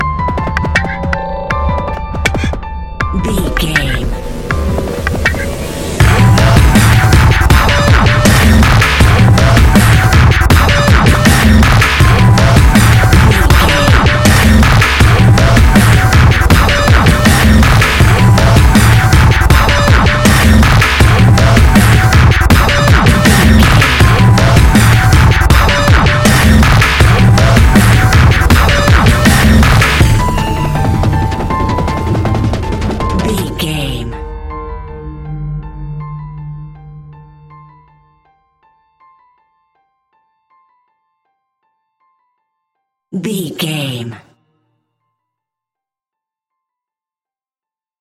In-crescendo
Aeolian/Minor
Fast
aggressive
dark
hypnotic
industrial
heavy
drum machine
synthesiser
piano
breakbeat
energetic
instrumentals
synth leads
synth bass